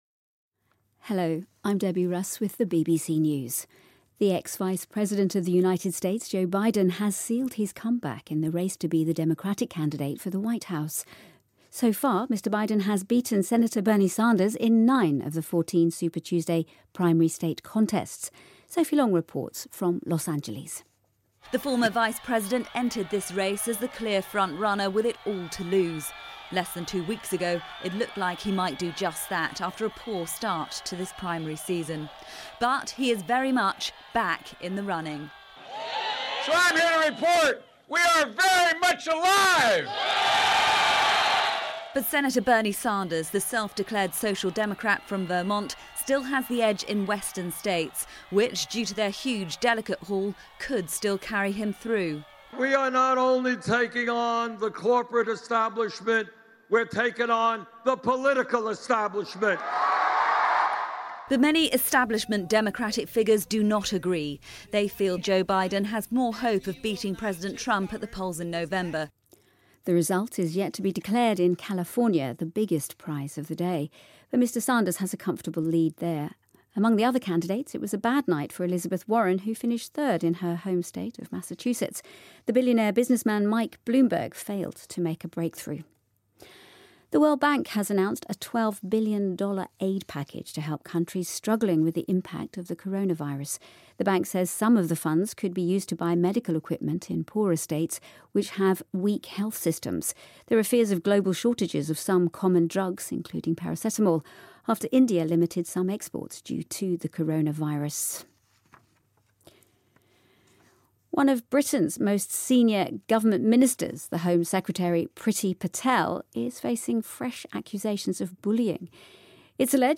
News
英音听力讲解:拜登在超级星期二逆袭成赢家